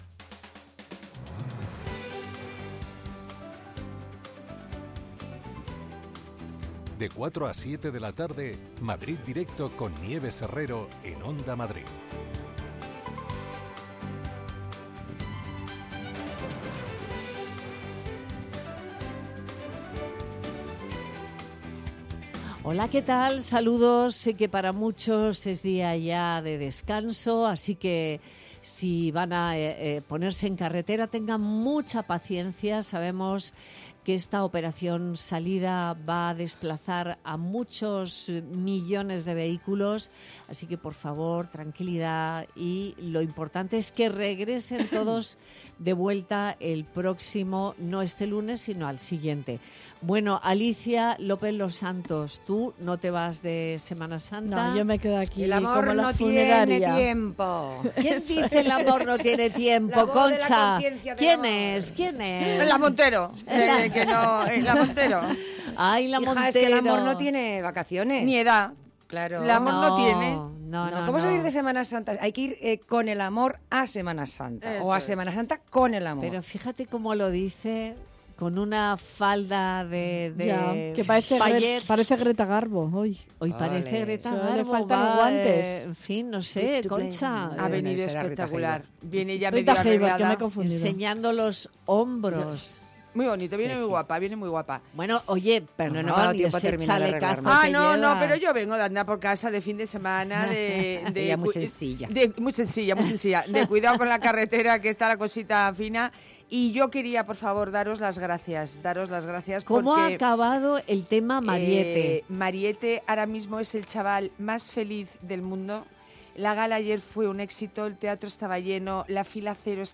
Nieves Herrero se pone al frente de un equipo de periodistas y colaboradores para tomarle el pulso a las tardes. Tres horas de radio donde todo tiene cabida. La primera hora está dedicada al análisis de la actualidad en clave de tertulia.